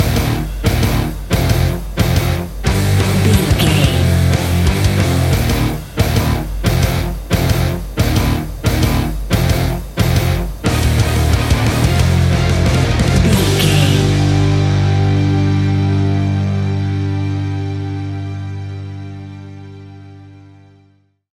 Epic / Action
Fast paced
Aeolian/Minor
hard rock
heavy metal
instrumentals
Heavy Metal Guitars
Metal Drums
Heavy Bass Guitars